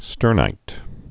(stûrnīt)